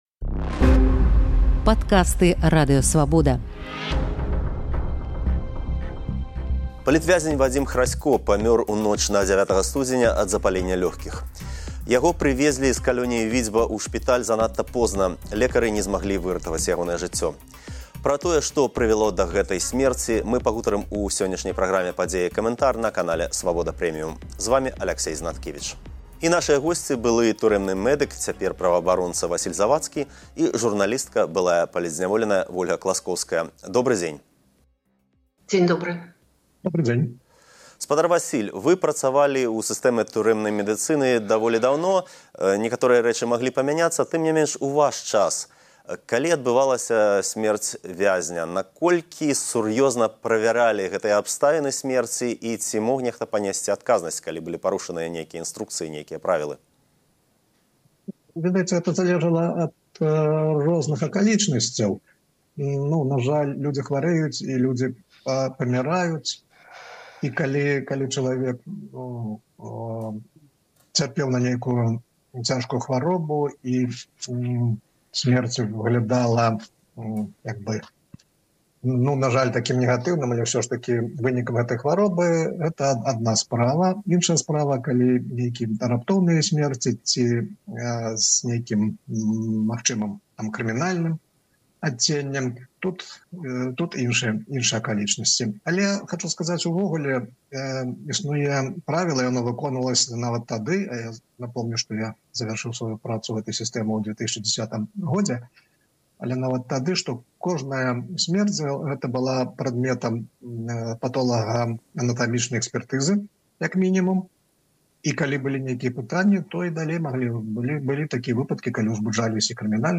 Былы турэмны мэдык і палітзьняволеная пра сьмерць за кратамі